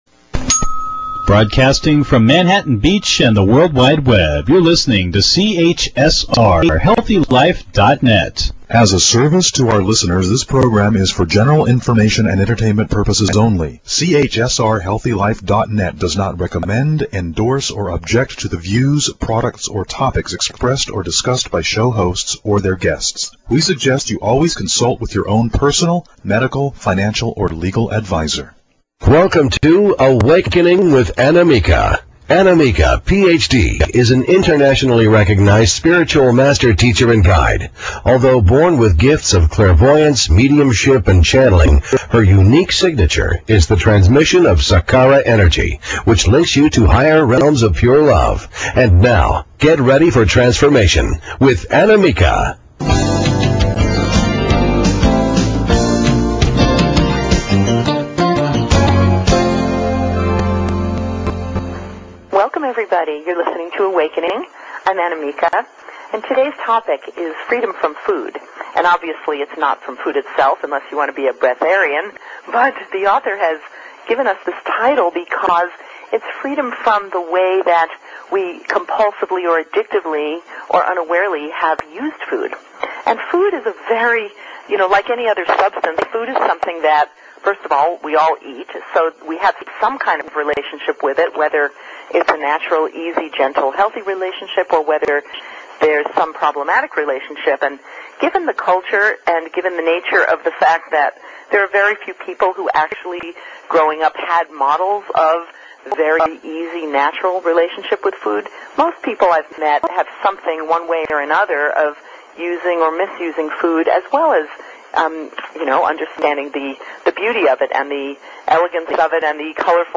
Radio Shows